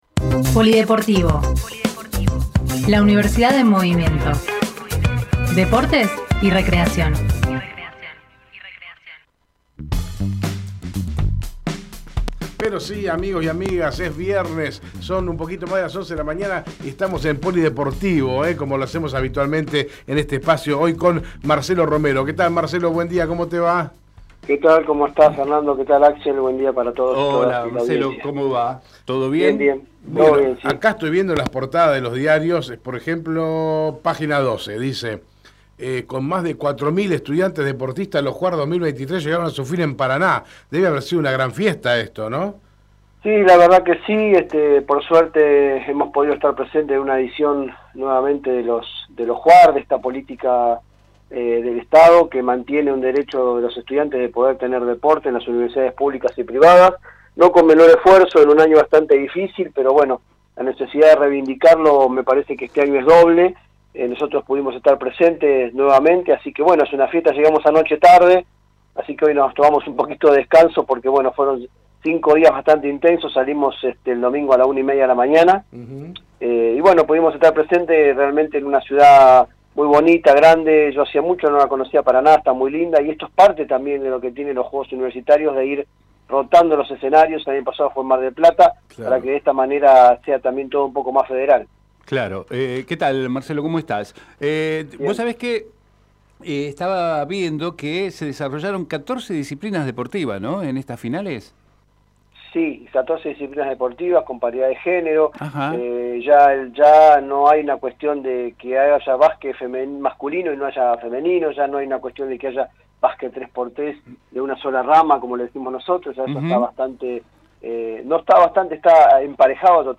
POLIDEPORTIVO todos los viernes en Hacemos PyE el espacio de los deportes y recreación de la universidad, la UNDAV en movimiento. Esta semana conversamos con